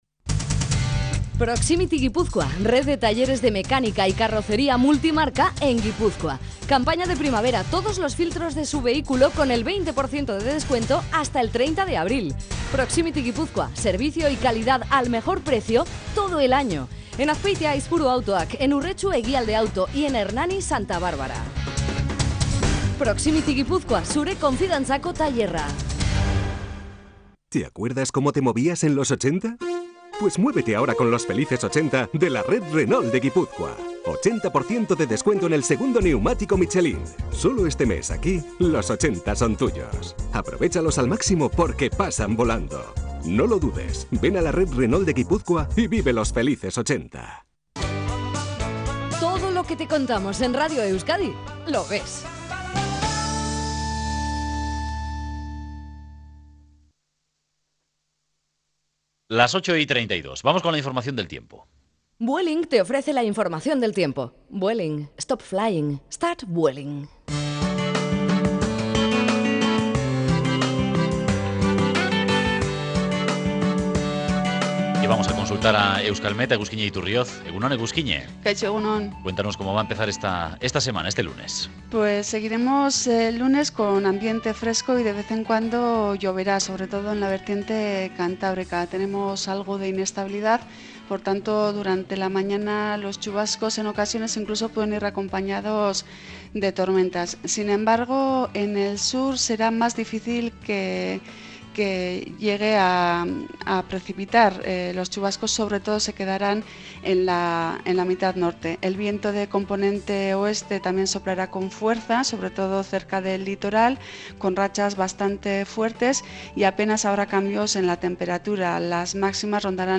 Entrevista a Oscar Arizcuren, nuevo secretario general de UPN